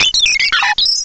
cry_not_starly.aif